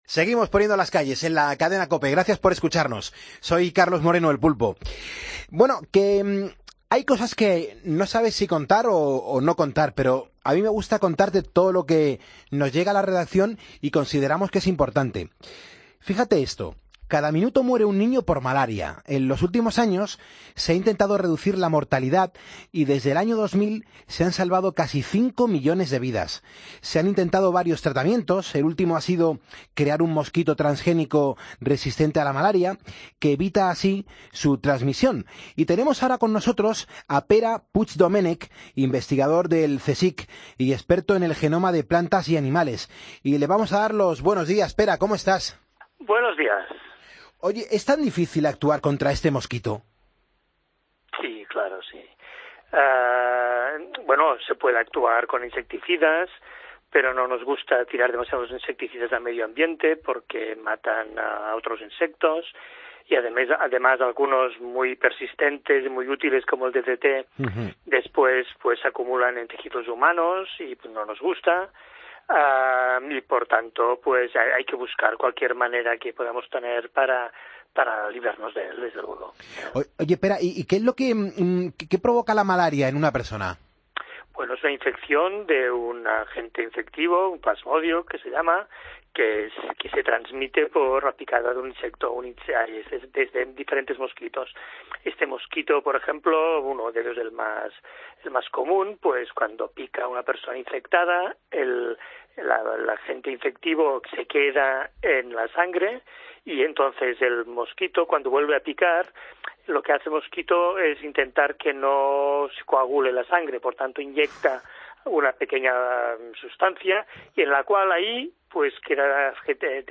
Hablamos con el investigador del CSIC